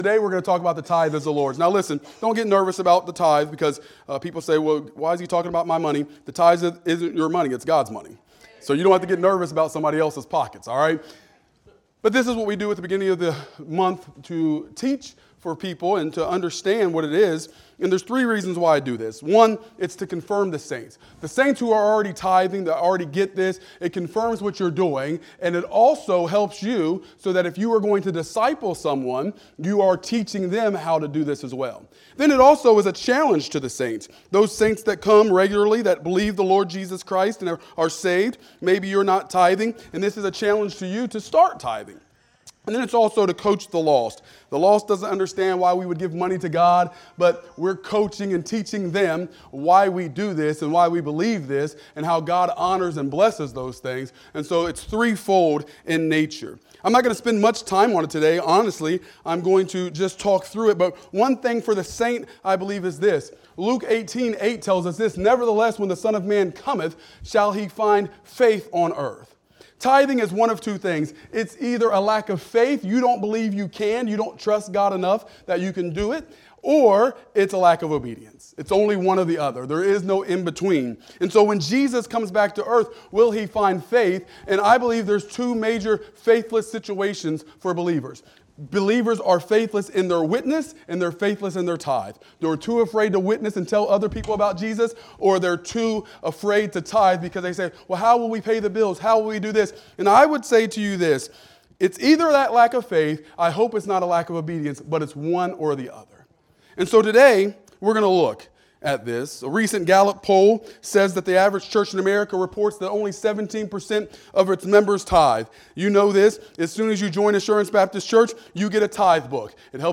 Morning Worship Service